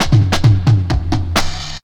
17DR.BREAK.wav